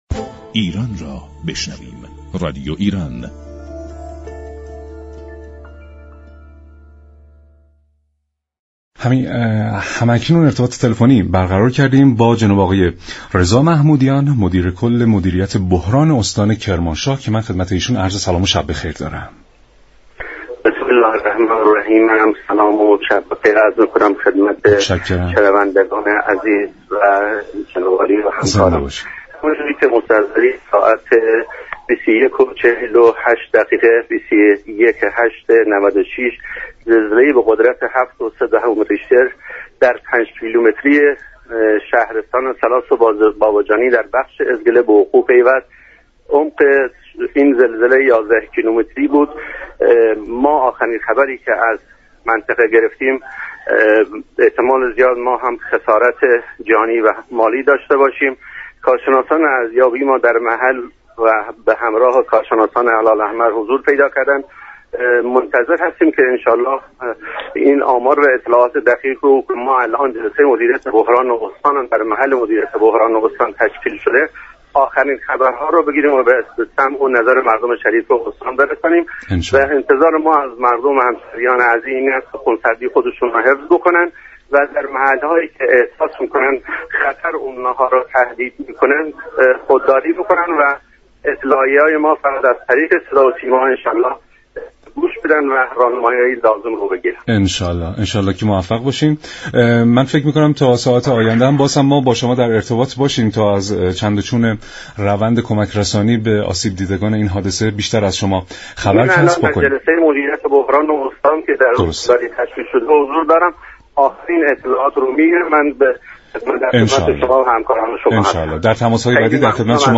رضا محمودیان مدیر كل مدیریت بحران استان كرمانشاه در رادیو ایران گفت